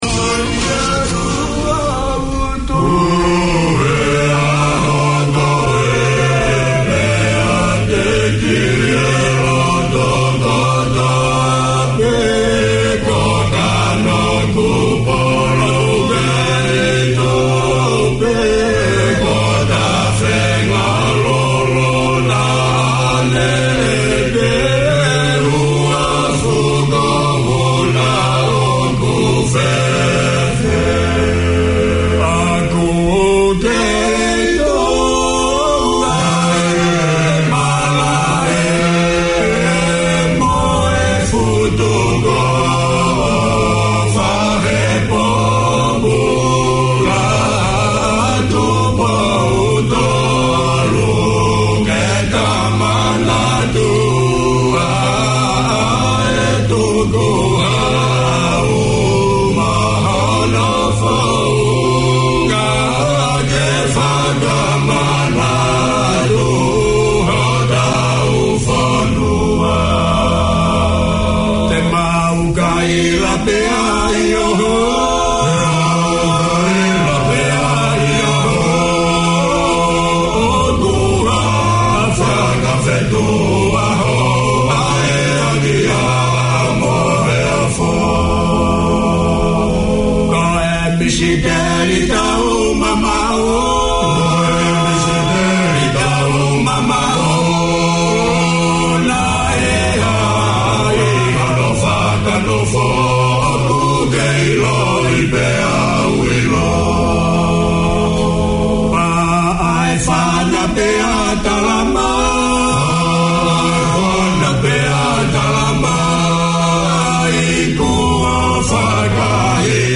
Talks to parents about being young and Tongan in NZ. A chance for the generations to dialogue and hear Tongan music, traditional and modern.